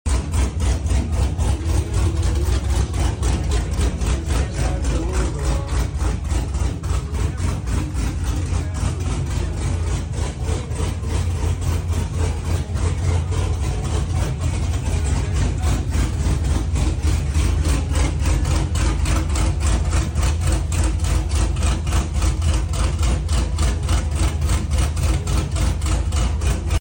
Custom cut stage 4 turbo sound effects free download
Custom cut stage 4 turbo cam from btr sounding mean!